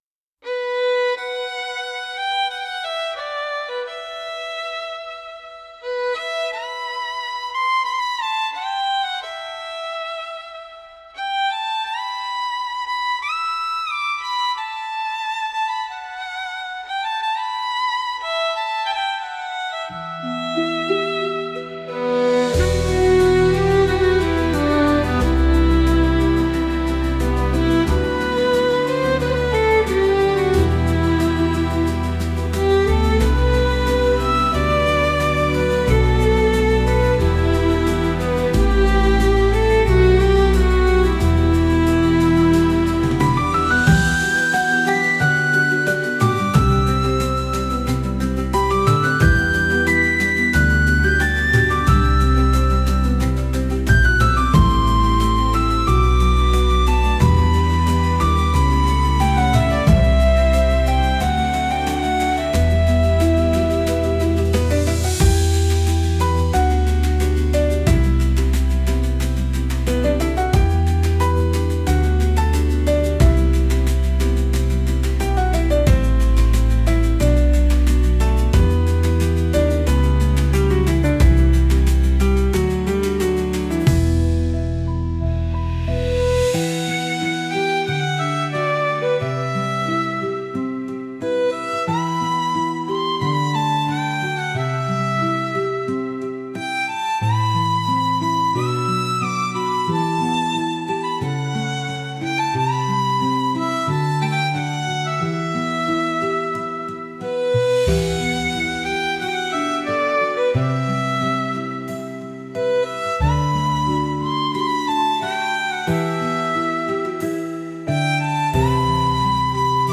歴史と切なさを感じさせるようなケルト音楽です。